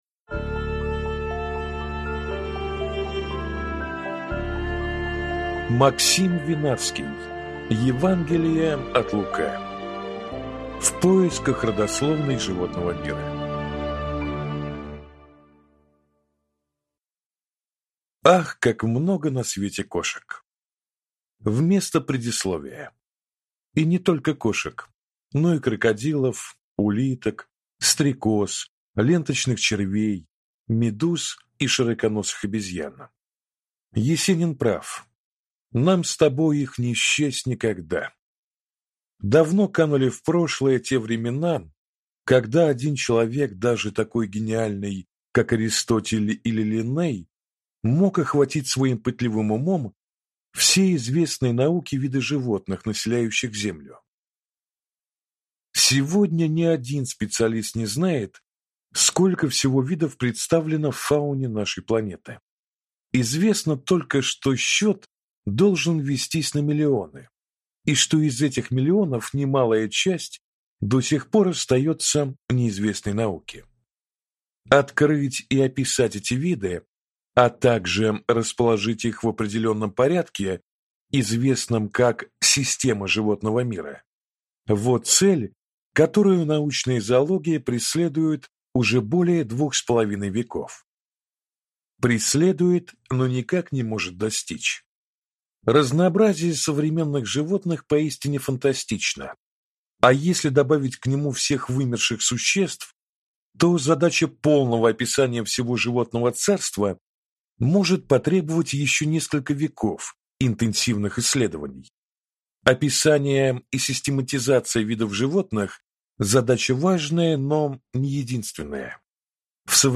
Аудиокнига Евангелие от LUCA. В поисках родословной животного мира | Библиотека аудиокниг